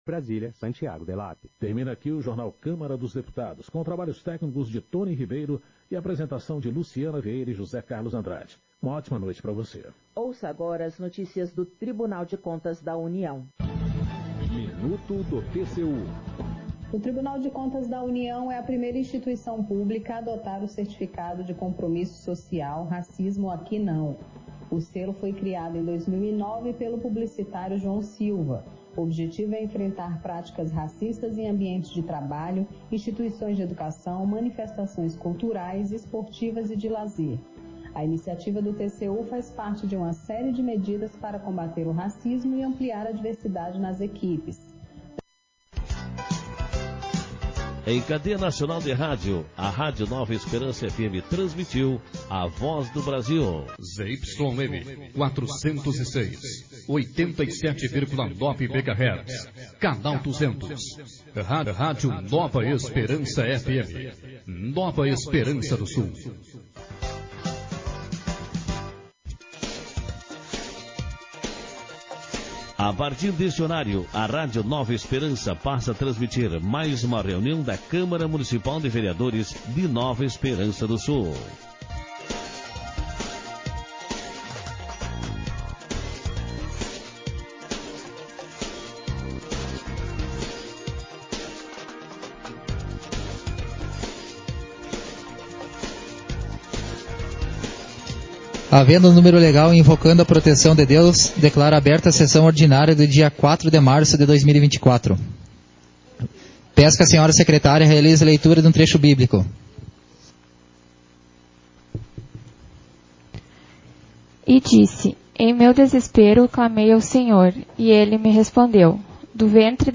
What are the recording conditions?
Sessão Ordinária 05/2024